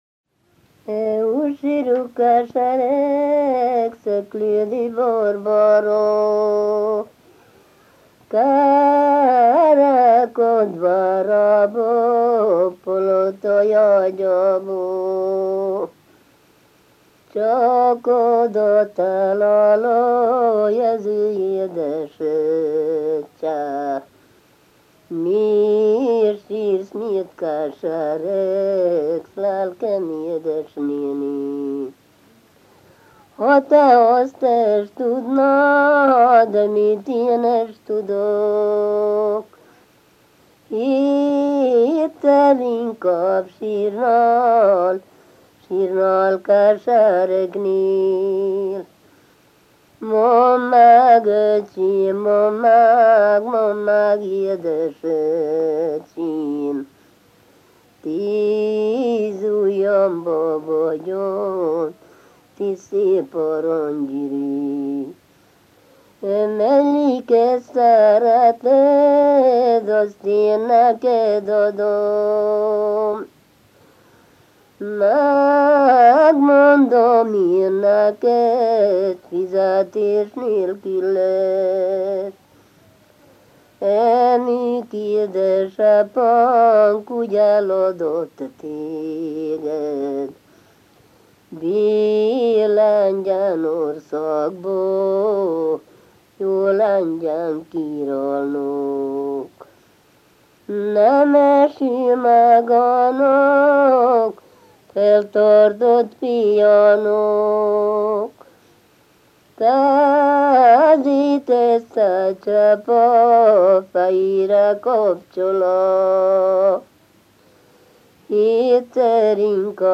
Szövegkezdet: Úgy sír, úgy kesereg Szeklédi Borbála > Műfaj: Ballada > Helység: Klézse > Vármegye: Moldva